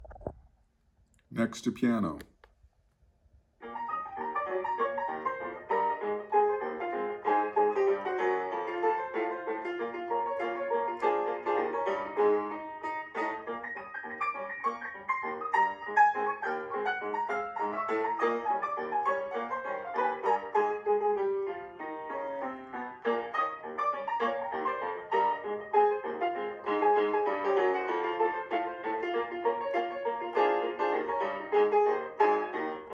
I'm trying to record myself playing acoustic piano at home.
It worked well, until it didn't. The recorded sound began to fade in and out and was distorted.
The laptop sounds like it's an auto gain control, so it's pumping from the volume, trying to keep things from overloading.